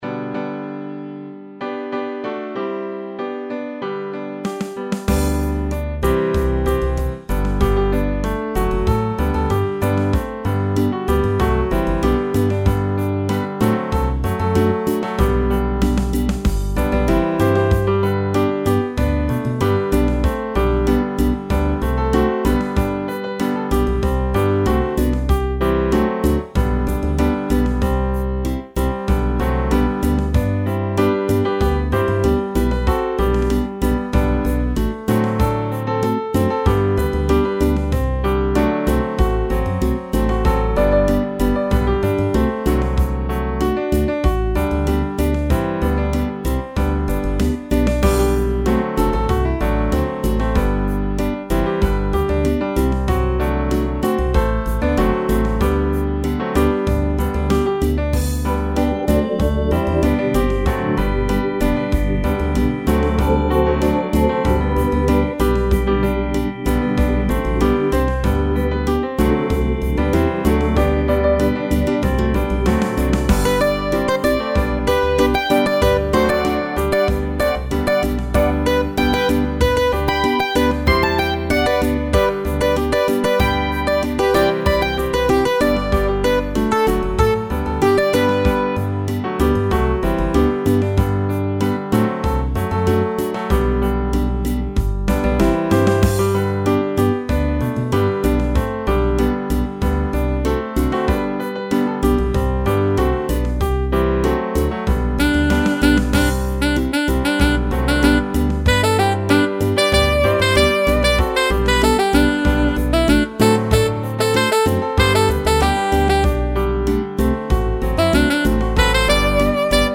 South African piano improvisations.
African Jazz-Piano